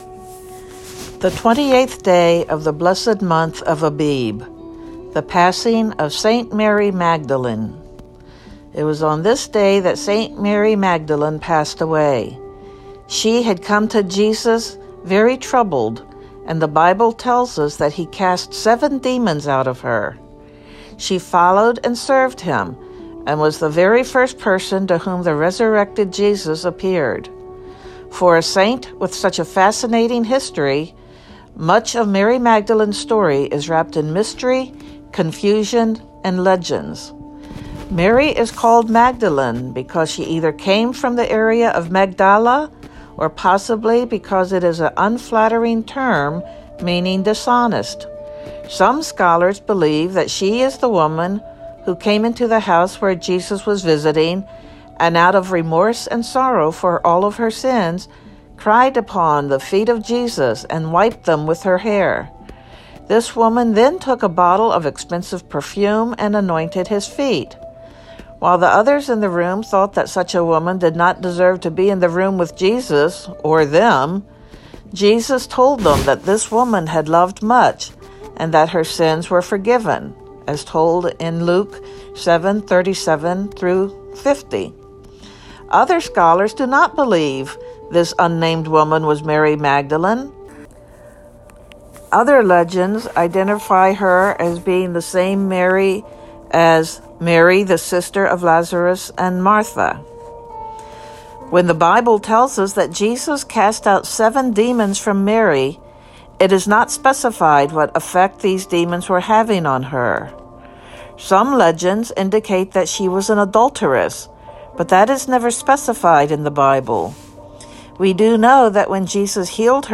Synaxarium readings for the 28th day of the month of Abib